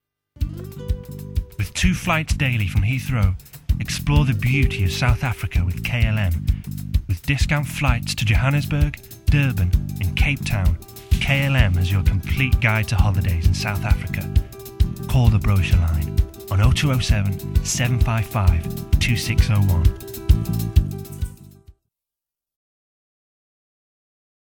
Voice Reel
KLM - Upbeat, Informative